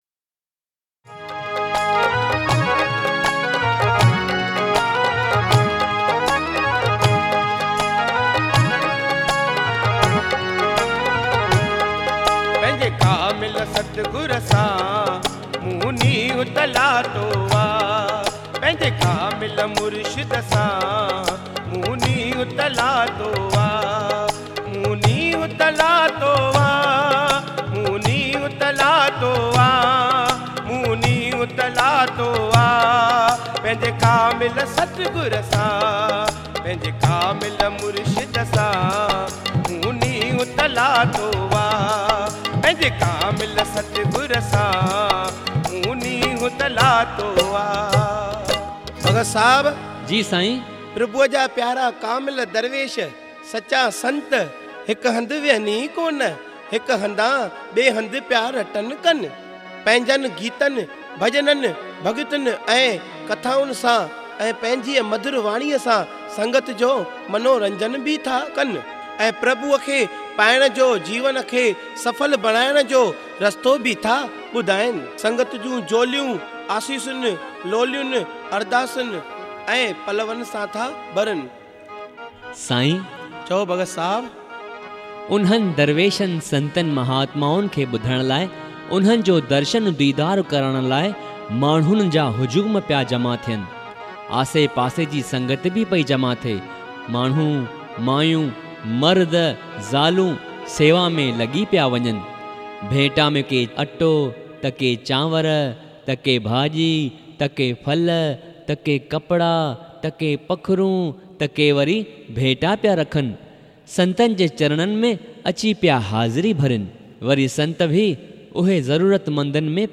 Bhajans and Dhuni songs